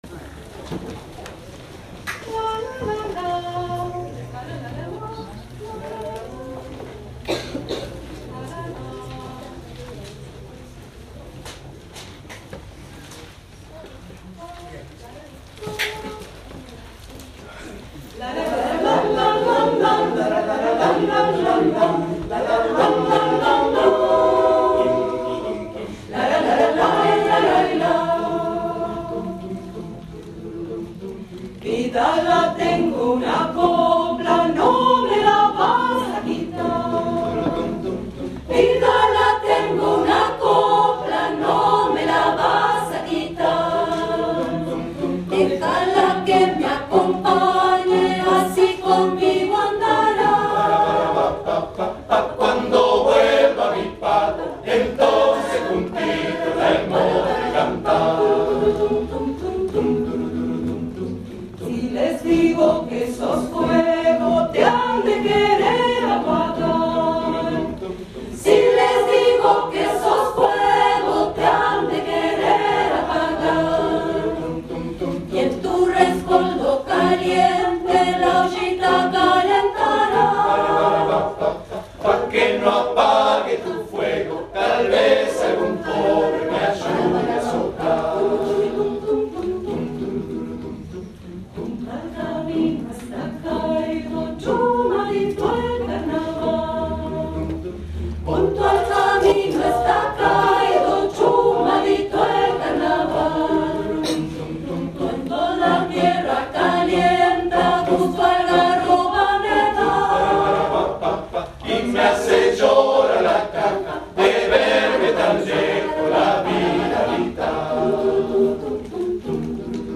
Vidala chayera.